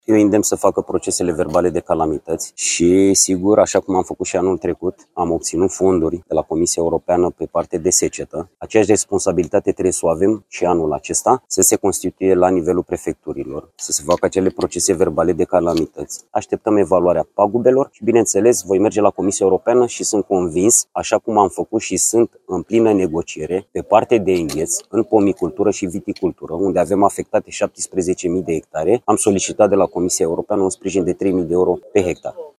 Prezent în orașul Nădlac, la o întâlnire cu femierii, ministrul Agriculturii, Florin Barbu, le-a recomandat acestora să pregătească procesele verbale de calamități, asigurându-i că ajutorul statului va fi la fel ca anul trecut.